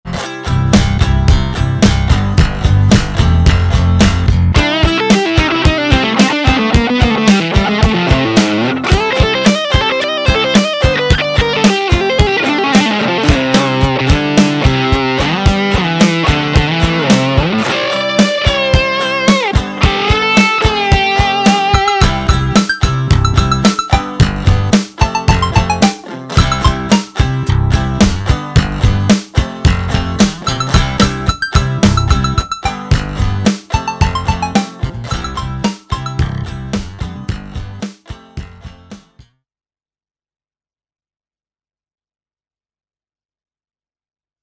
A guitar solo excerpt from an original tune entitled “The Ascension Of Bevan”. The guitar is shamelessly cheesy 80’s shreddy rock.
electric-guitar-solo1.mp3